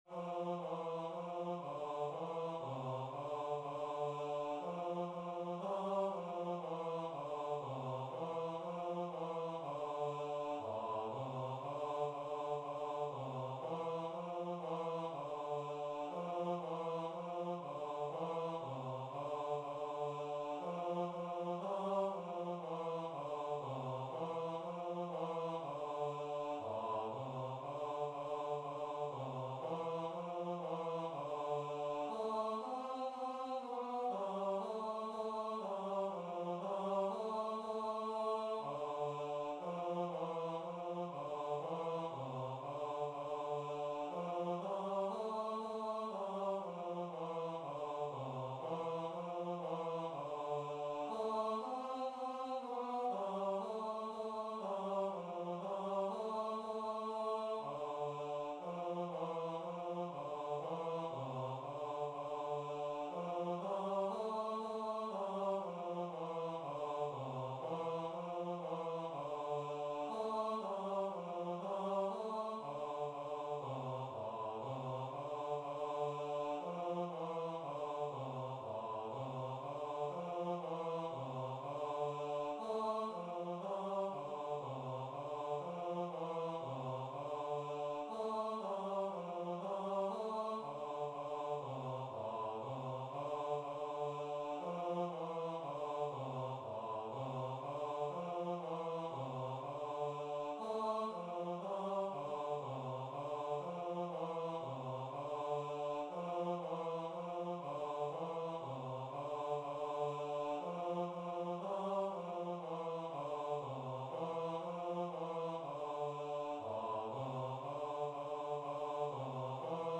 Choir version
4/4 (View more 4/4 Music)
Choir  (View more Easy Choir Music)
Classical (View more Classical Choir Music)